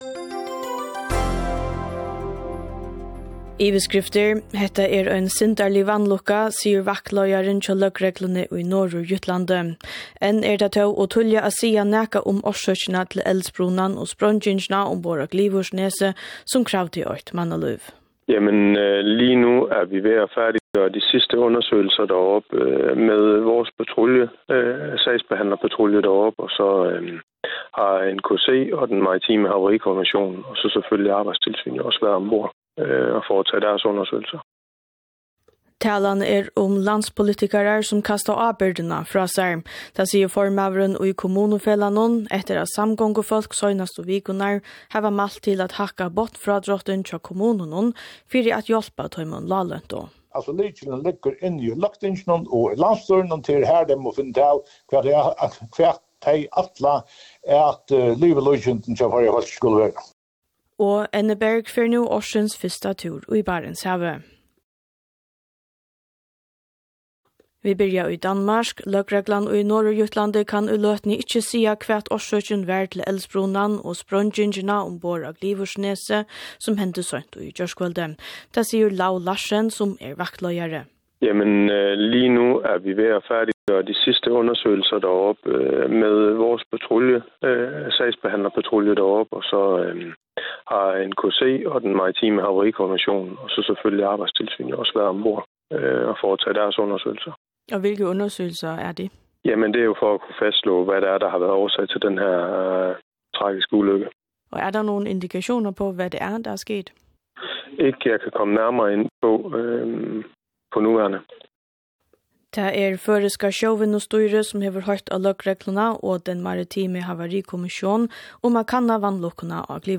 … continue reading 63 episoder # faroe islands # News # Kringvarp Føroya